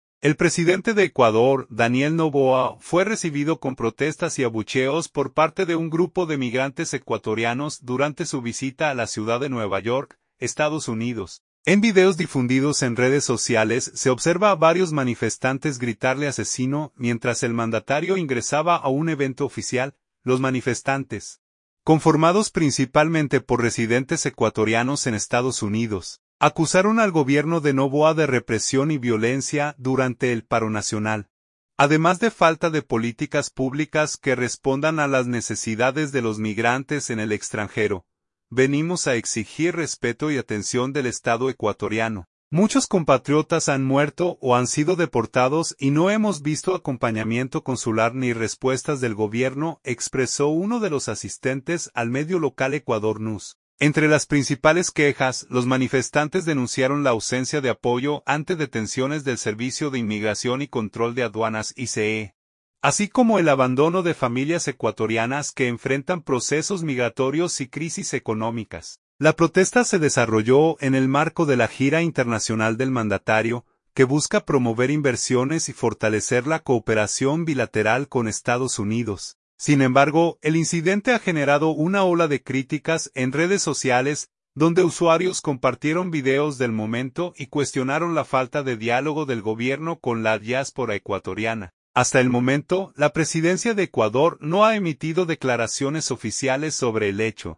Migrantes ecuatorianos abuchean al presidente Daniel Noboa en NY y lo acusan de “asesino”
En videos difundidos en redes sociales se observa a varios manifestantes gritarle “¡asesino!” mientras el mandatario ingresaba a un evento oficial.